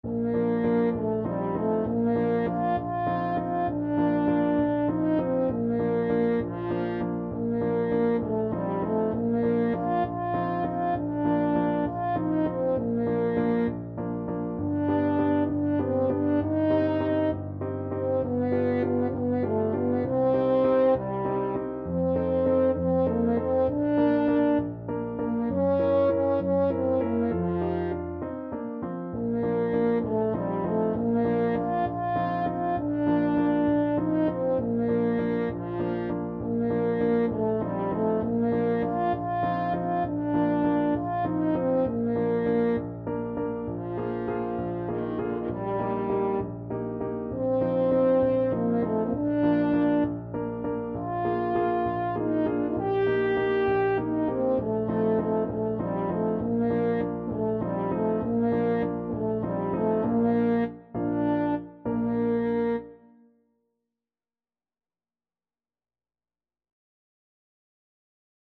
6/8 (View more 6/8 Music)
. = 66 No. 3 Grazioso
Classical (View more Classical French Horn Music)